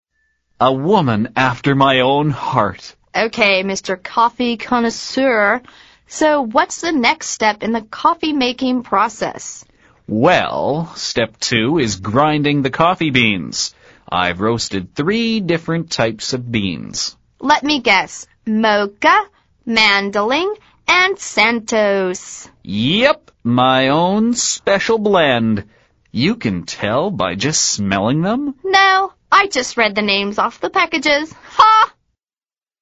美语会话实录第98期(MP3+文本):A woman after my own heart